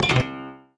Block Catapult Sound Effect
Download a high-quality block catapult sound effect.
block-catapult-2.mp3